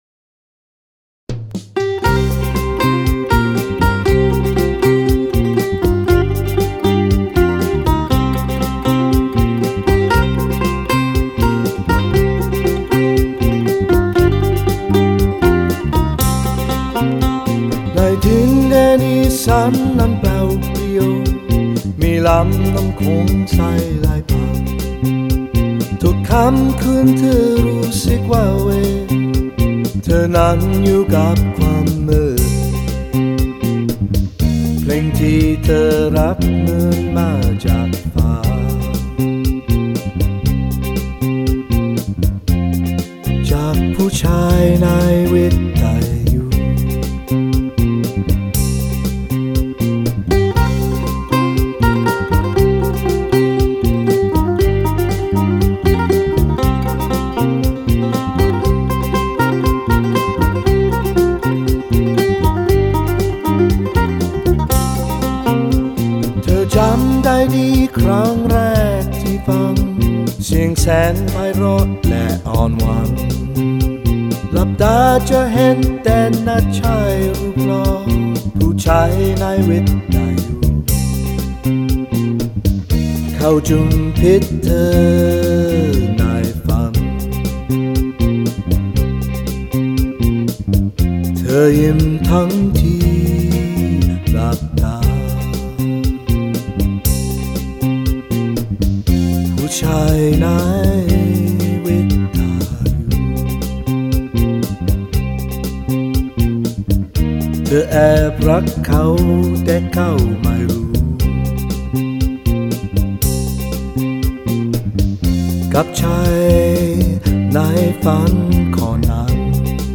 It is recorded here in both languages and styles of music. https